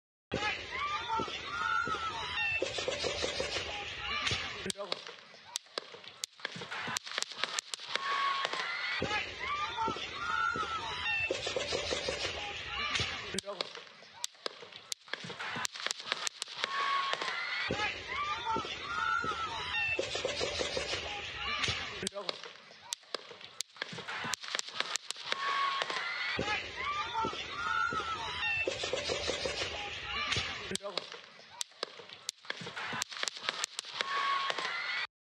I made this audio file where I cut both shot bursts from C-SPAN video and the second video used in Chris Martenson's analysis, and added eq (boosted several frequencies) in order to see if the differences can be brought up more audible in the C-SPAN audio also. I think the echoes are a bit more audible now. I also noticed that the second burst of shots are few db louder in C-SPAN video, which could maybe support the evidence of shots being fired from different locations?
(audio clips repeat four times with same eq setting, I've cut the time between bursts to make it easier to compare). Attachments TRUMP_SHOOTING.mp3 TRUMP_SHOOTING.mp3 510.7 KB